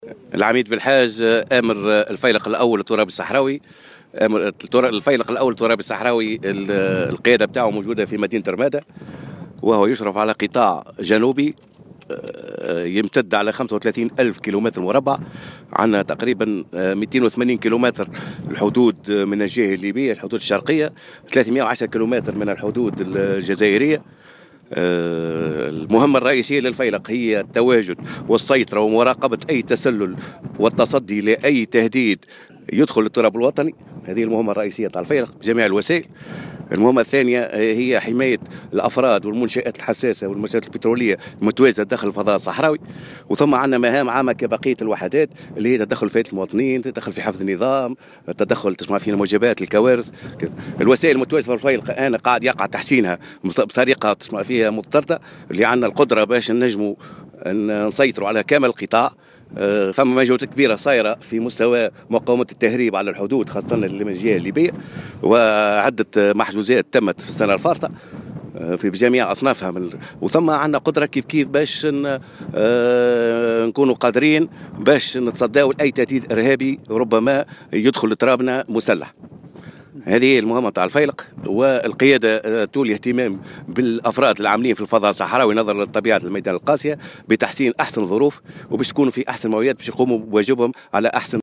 وأوضح على هامش زيارة ميدانية، نظمتها اليوم وزارة الدفاع الوطني لفائدة إعلاميين، ان الفيلق الترابي الصحراوي يغطي الحدود التونسية وقيادته متواجدة منطقة رمادة الصحراوية ويحمي الحدود التونسية من جهة ليبيا على طول 280 كلم ومن جهة الجزائر بنحو 310 كلم. وأضاف أن مهام الفيلق تتمثل بالأساس في حماية التراب الوطني من التهريب والتسلل والارهاب بالاضافة الى حماية الأفراد والمنشآت الحساسة والبترولية داخل الفضاء الصحراوي.